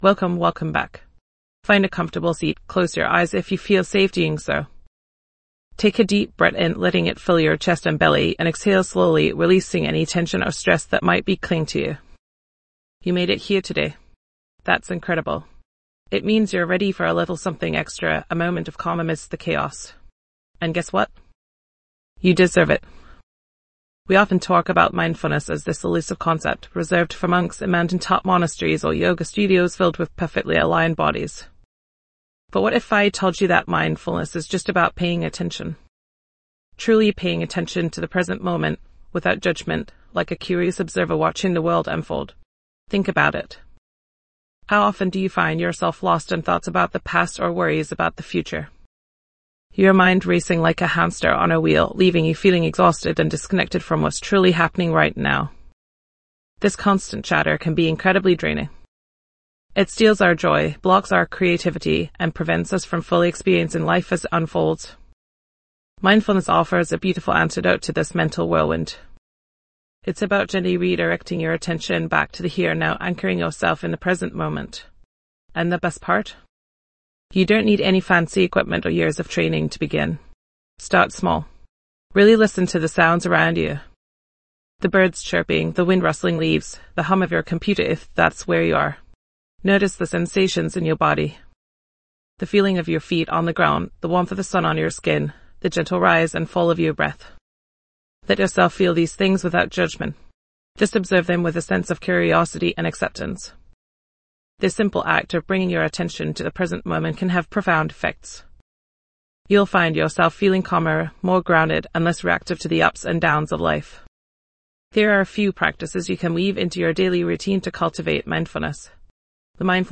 "Unplug & Recharge: Micro Meditation Daily" offers a sanctuary for your mind, providing you with short, powerful meditations designed to bring balance and rejuvenation into your day. Each daily episode delivers a concise 5-minute meditation, helping you to quiet the mental chatter, reduce stress, and cultivate a sense of calm amidst the chaos.